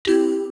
Index of /phonetones/unzipped/LG/A200/Keytone sounds/Sound2